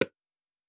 Click (25).wav